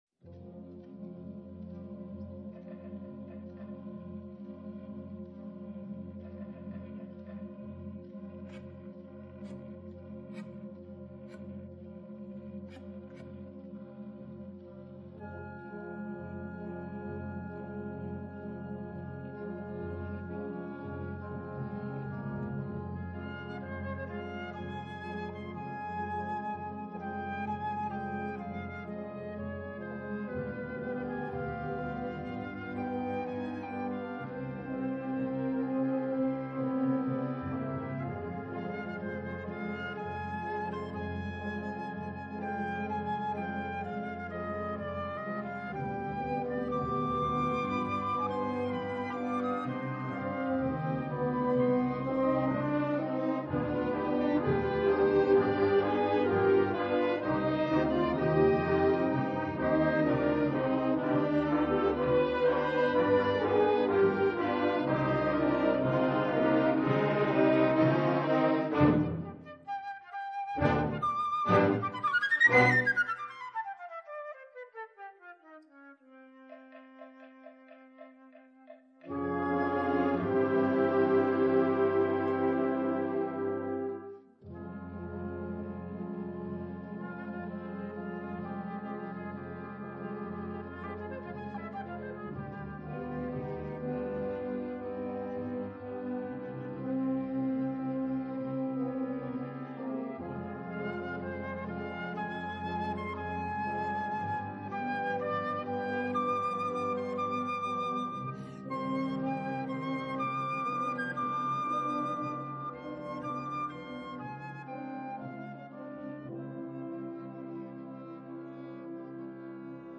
Concerto pour Flûte et Orchestre d'Harmonie